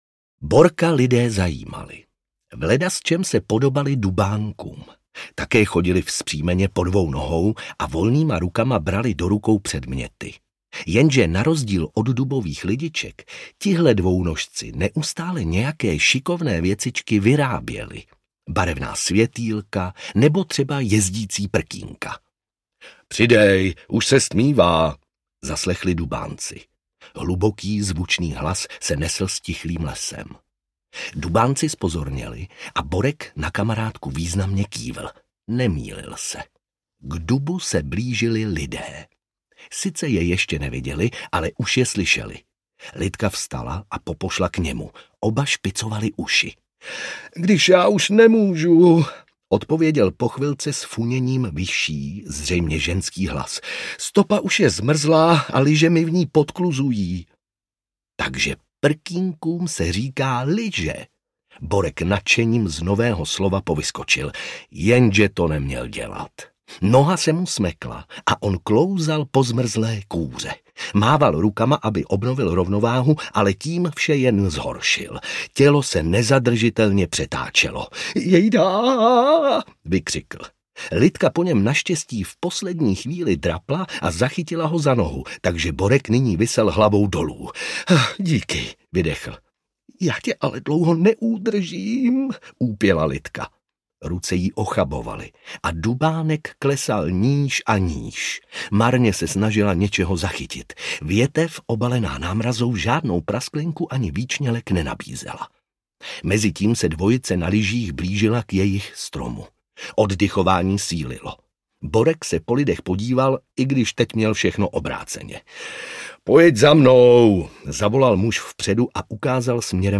Dubánek ve světě lidí audiokniha
Ukázka z knihy
Vyrobilo studio Soundguru.